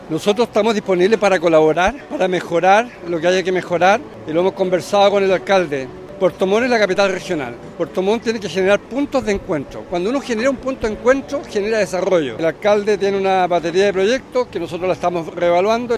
En las obras que pudiesen restar, el Gobernador Alejandro Santana comprometió apoyo desde el GORE.
calle-varas-gobernador.mp3